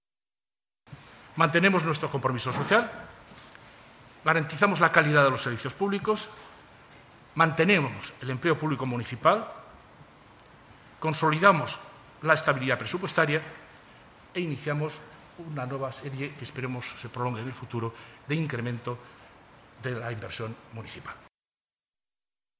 La valoración que ha realizado el alcalde de Zaragoza, Juan alberto Belloch, sobre el proyecto de presupuesto ha sido la siguiente: